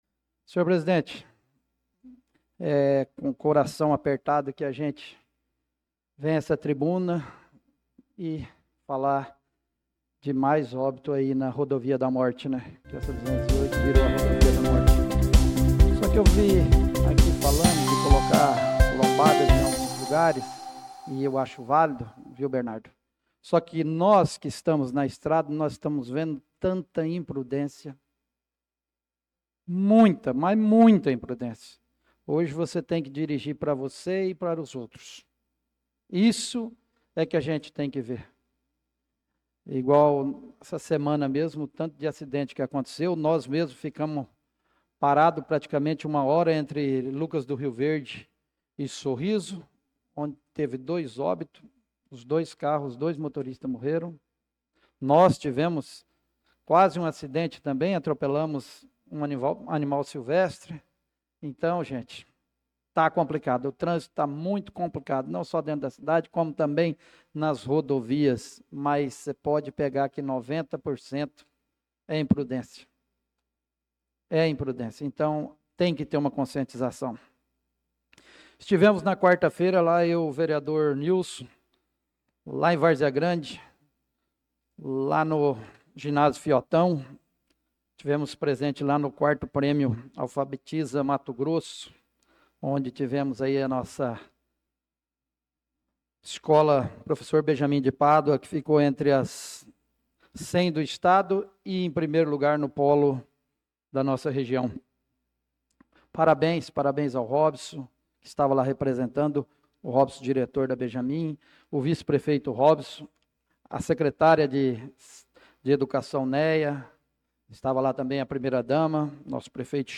Pronunciamento do vereador Marcos Menin na Sessão Ordinária do dia 25/08/2025.